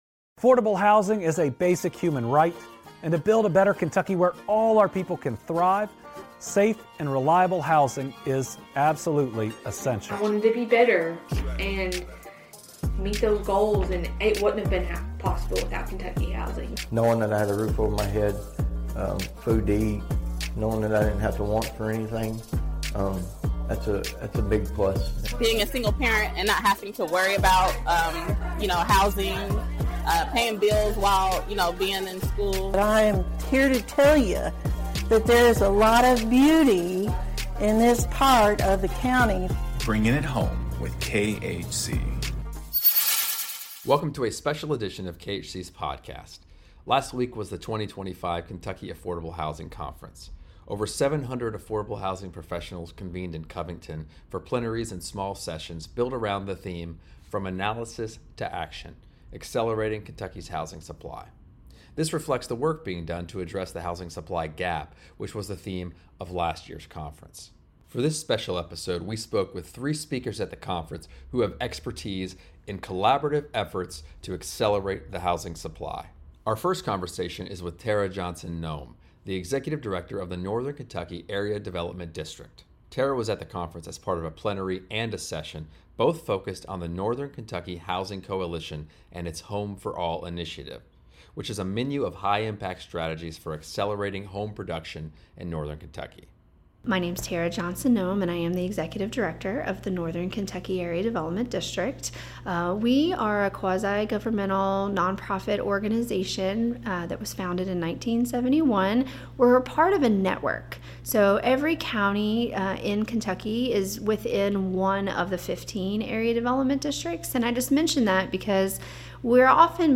In this special edition, recorded live at the 2025 Kentucky Affordable Housing Conference, we explore collaborative strategies to accelerate housing supply across the region.